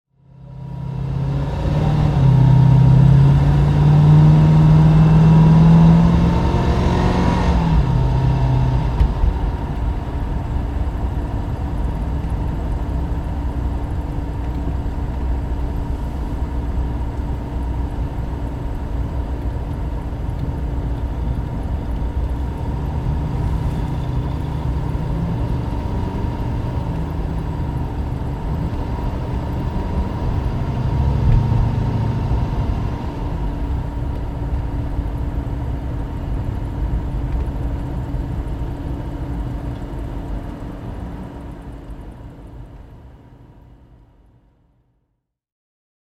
Motorsounds und Tonaufnahmen zu Aston Martin Fahrzeugen (zufällige Auswahl)
Aston Martin Lagonda (1984) - Fahrgeräusch (innen)
Aston_Martin_Lagonda_1984_-_innen.mp3